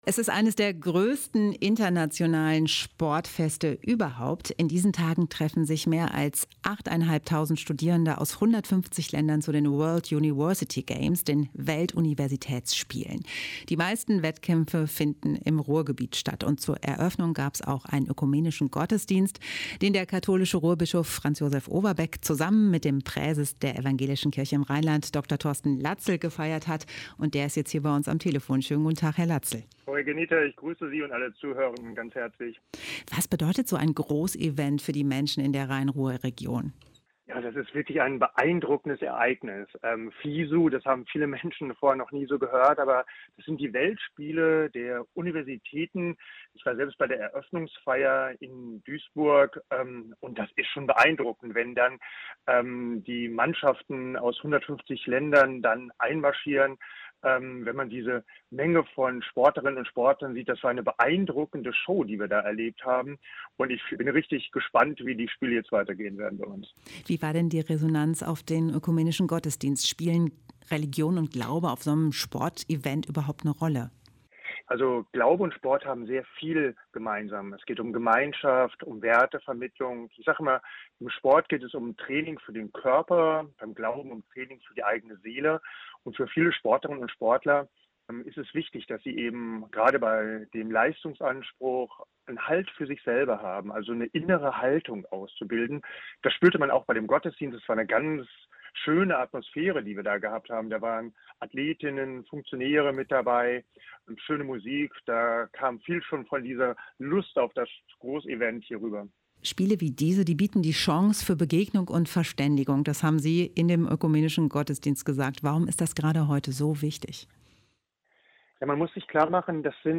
Ein Interview mit Thorsten Latzel (Präses der Evangelischen Kirche im Rheinland)